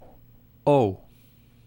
Ääntäminen
IPA: [o]